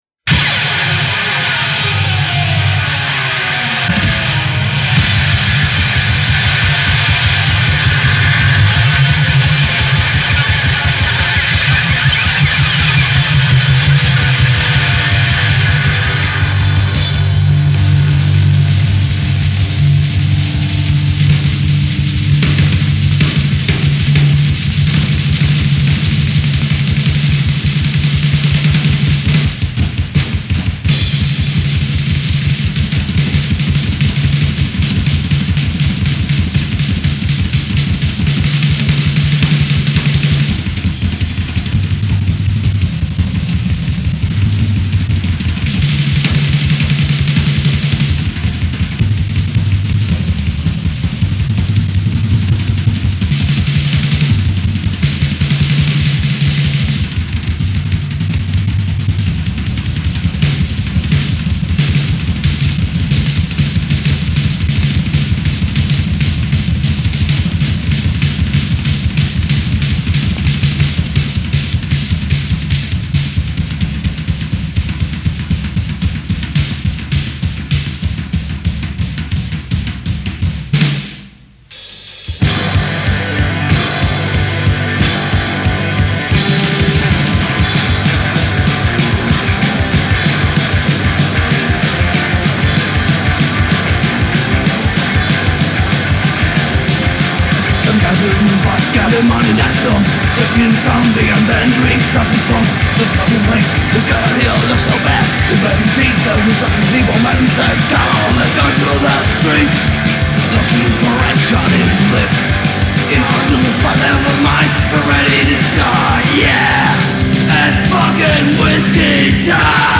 Album Reviews, Black Metal, Genre, Heavy Metal, Thrash Metal
The album is laden with killer riffs and hooks
the unforgettable party-banger